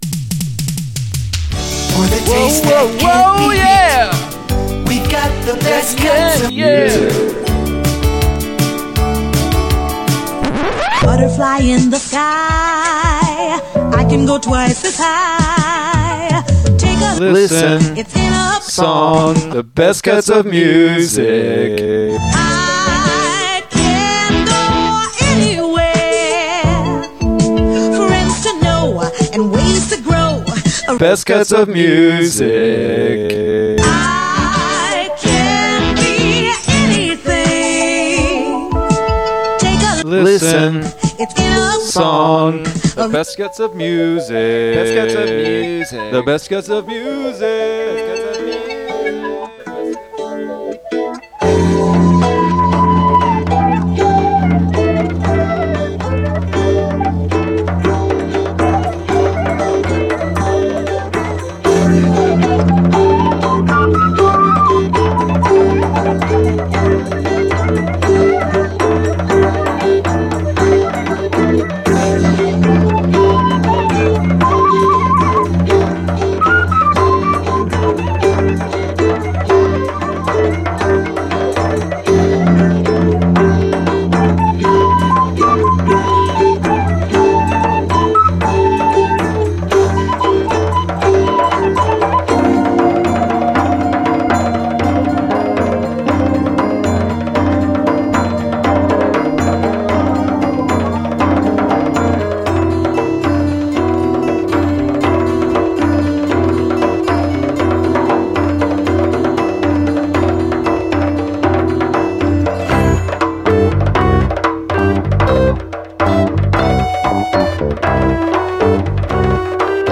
This is a magical episode that encourages its listeners to listen to music and to stretch the boundaries of everyday sound that you might encounter on a daily basis. You’ll get a variety of songs reviewed for you by some very cute and diminutive tykes.